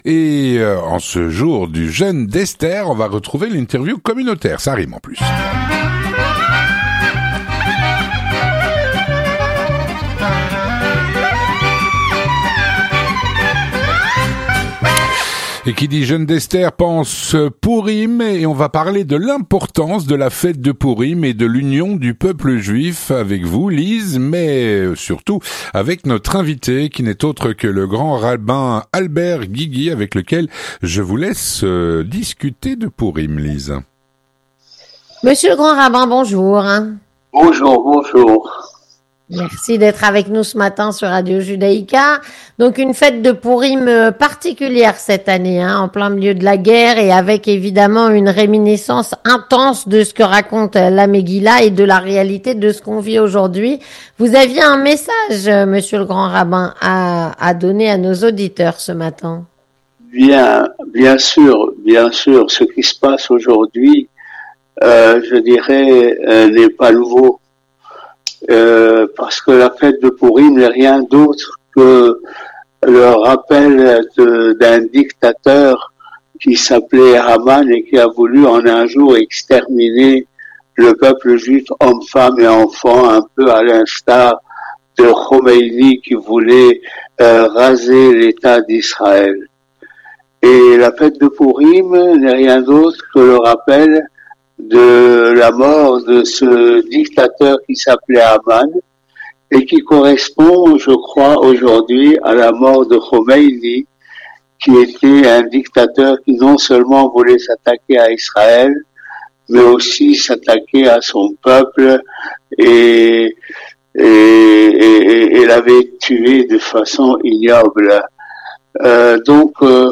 3. L'interview communautaire
Avec Albert Guigui, Grand rabbin de Bruxelles.